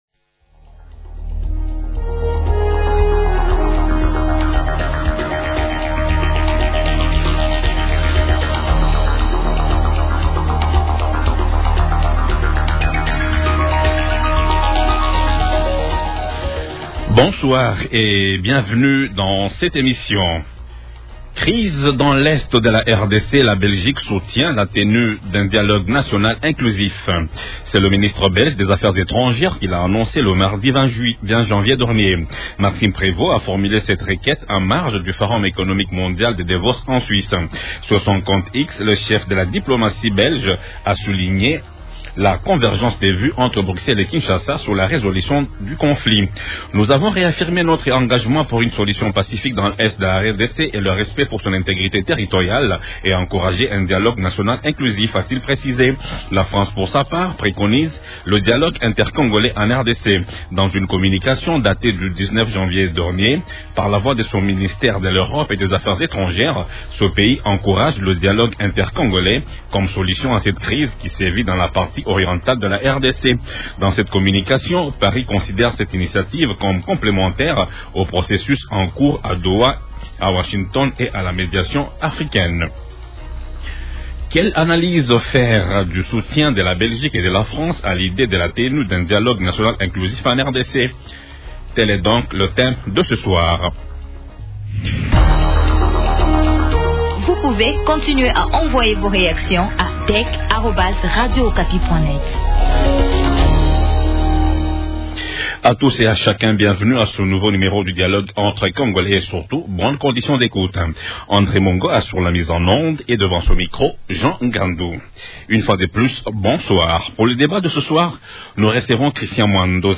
Question : -Quelle analyse faire du soutien de la Belgique et de la France qui plaident pour la tenue d’un dialogue national inclusif en RDC ? Invités : -Christian Mwando N’simba, député national élu de Moba dans la province du Tanganyaki, Il est cadre d’Ensemble pour la république, parti politique de l’opposition et président du groupe parlementaire Ensemble à l’Assemblée nationale.